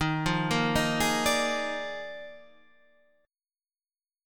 EbM7sus2sus4 Chord